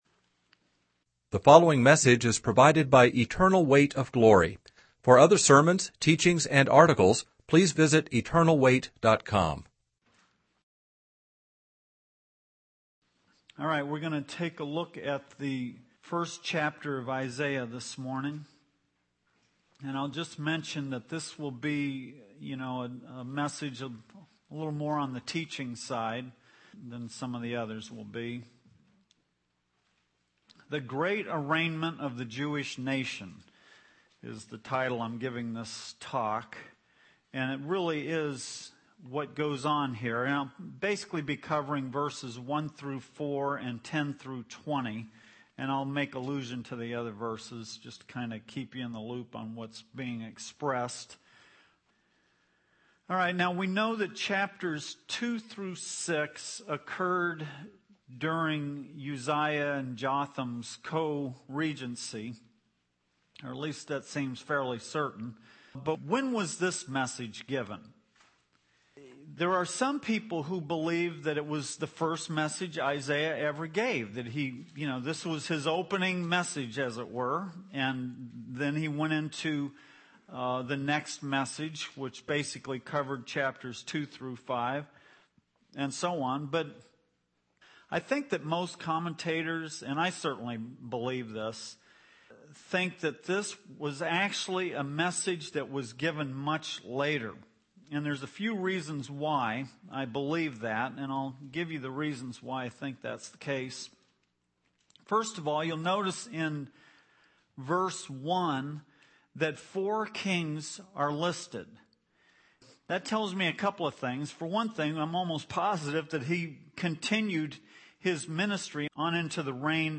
In this sermon, the preacher discusses Isaiah chapter 1 and focuses on the theme of the great arraignment of the Jewish nation. The sermon begins by explaining that the chapter serves as a trial of the Jewish people, showing them the consequences of their actions and offering them a chance to repent. The preacher highlights verses 2 and 4, which depict God's love for his people and their rebellion against him.